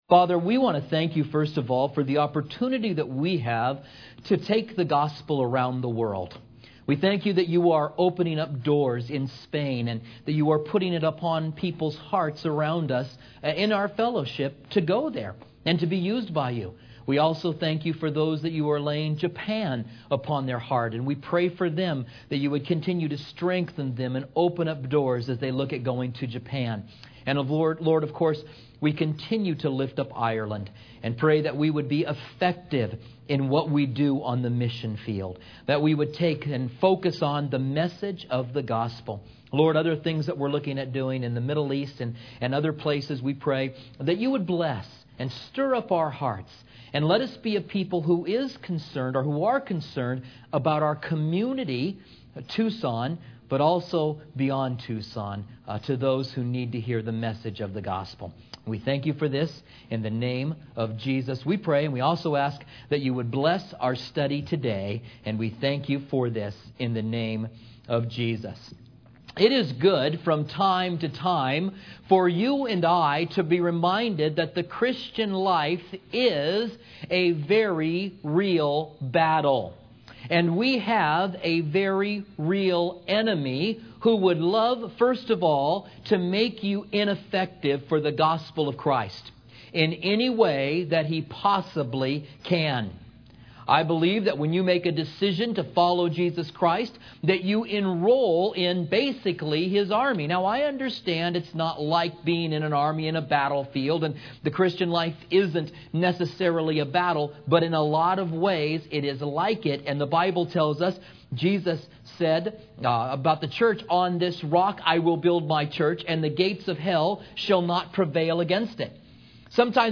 Commentary on 1 Kings